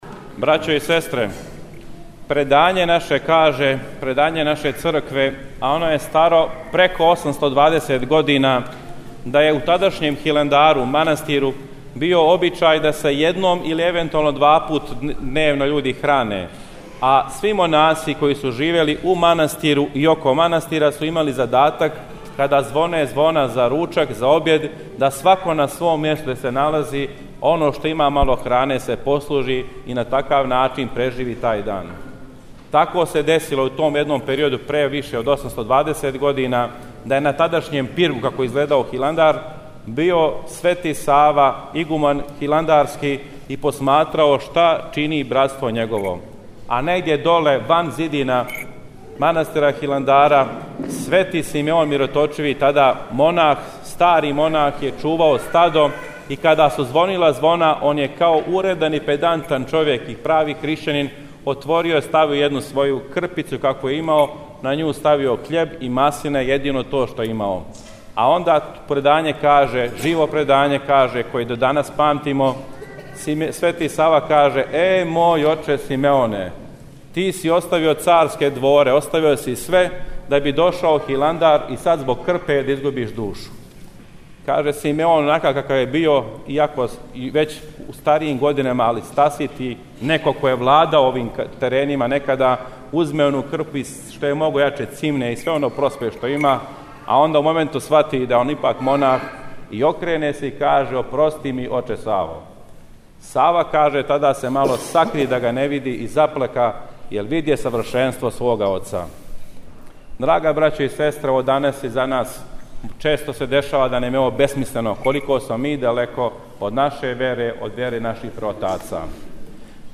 Викарни Епископ ремезијански г. Стефан (Шарић) служиo je у среду, 26. фебруара, Свету Литургију у Храму Светог Симеона Мироточивог на Новом Београду, поводом славе ове светиње посвећене родоначелнику светородне лозе Немањића.